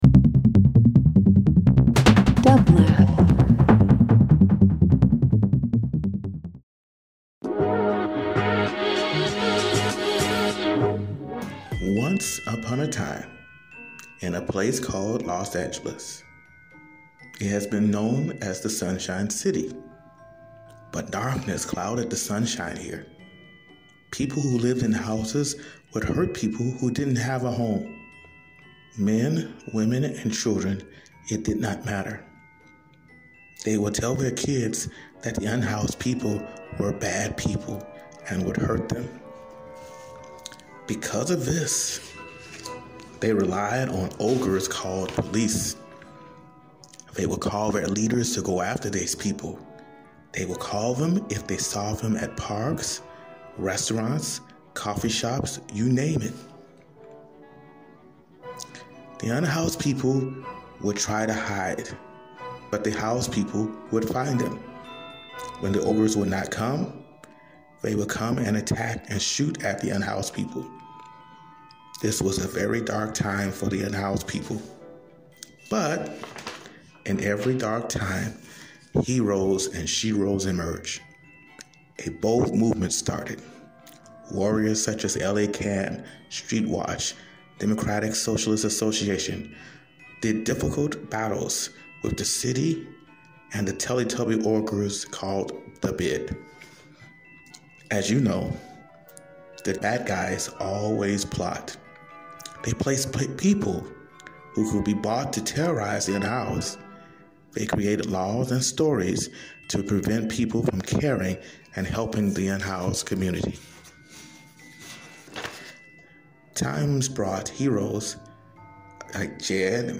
Field Recording Interview Talk Show